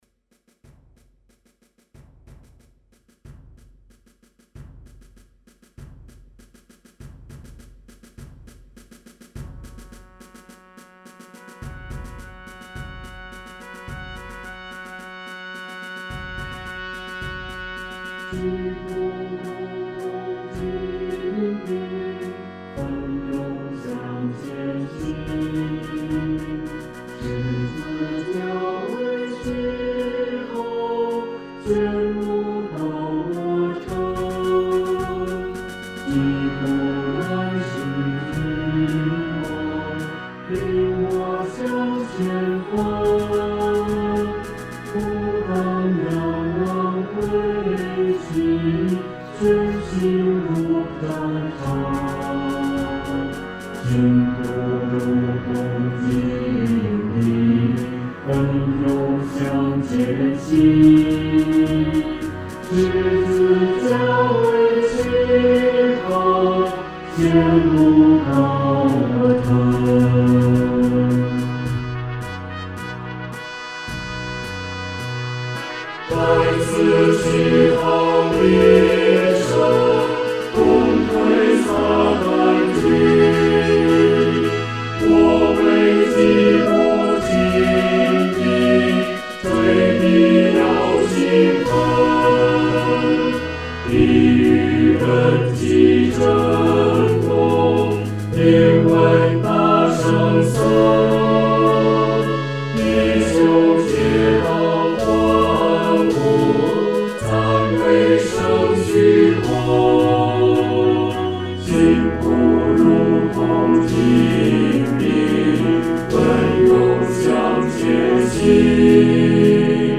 合唱
四声一
诗班在二次创作这首诗歌的时候，要清楚这首诗歌的音乐表情是进行曲风格。